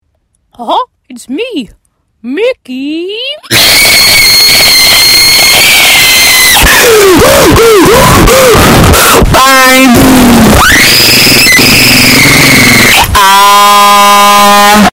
Mickey Mouse Earrape